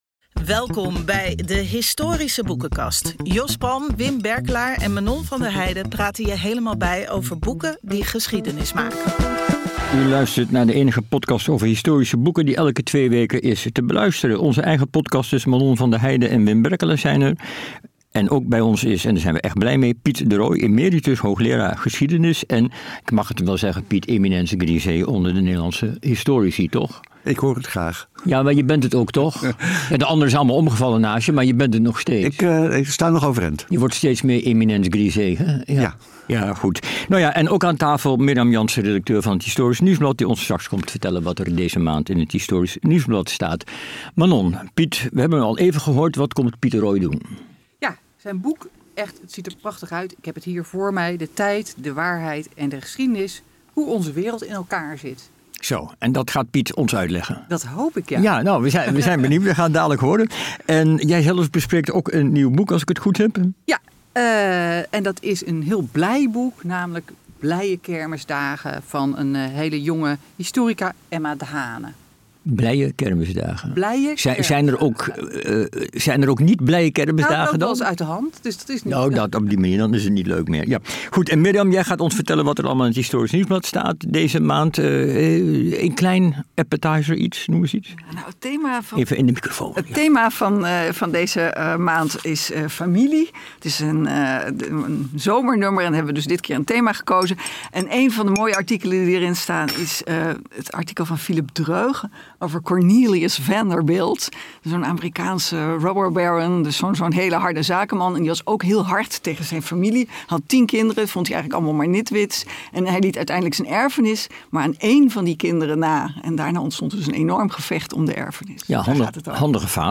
Blijf op de hoogte van de belangrijkste nieuwe geschiedenisboeken en luister elke maand naar boekbesprekingen en interviews met schrijvers.